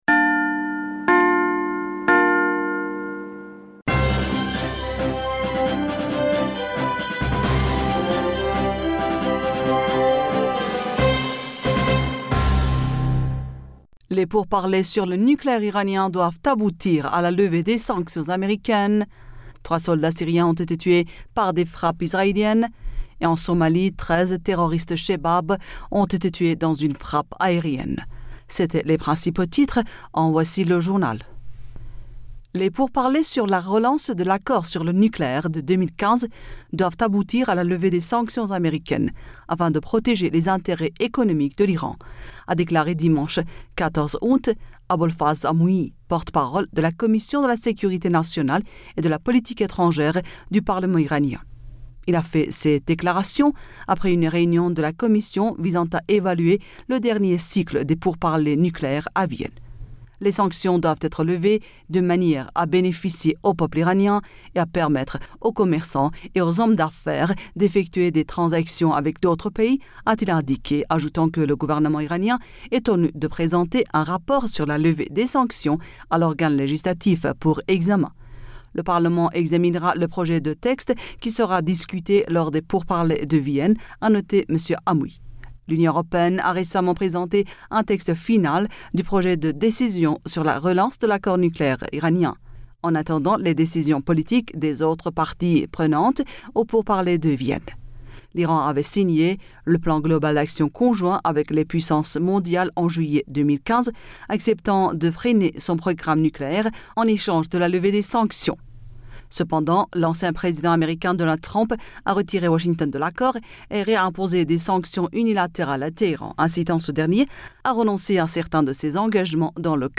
Bulletin d'information Du 15 Aoùt